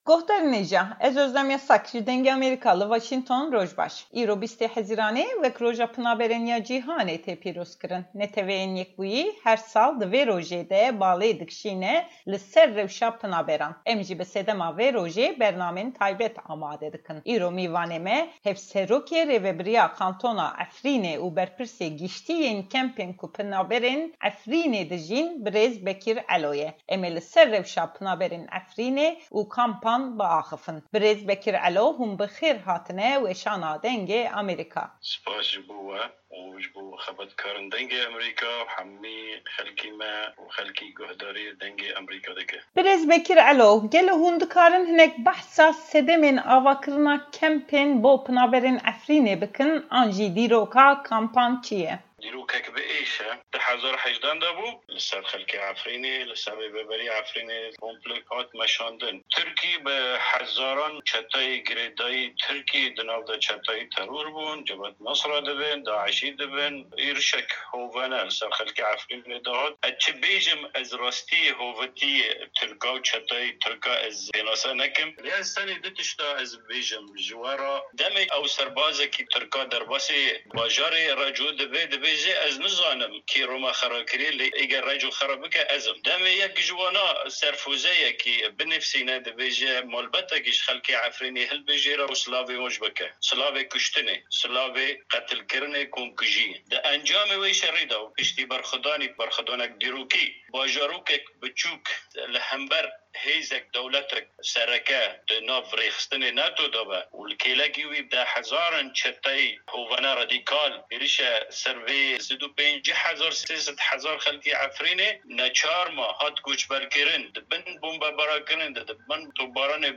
Hevpeyvina Hevserokê Rêvebirîya Qantona Efrîne Bekir Alo